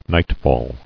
[night·fall]